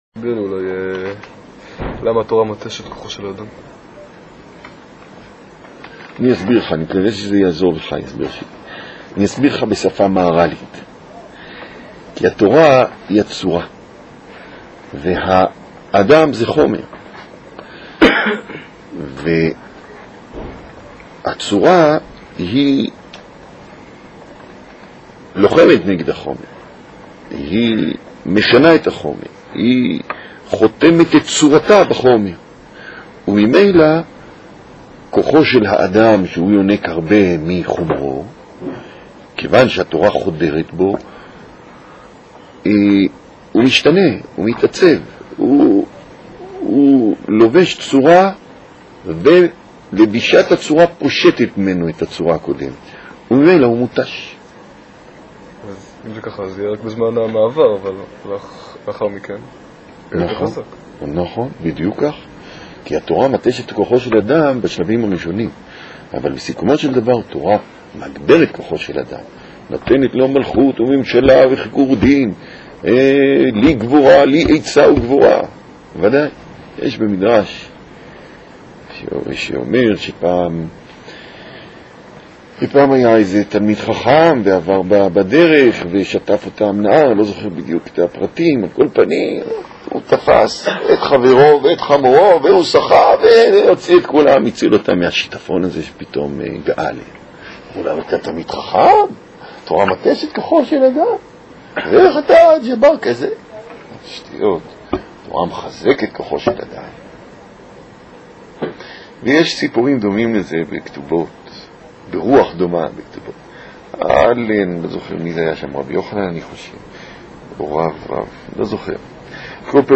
מתוך שו"ת. ניתן לשלוח שאלות בדוא"ל לרב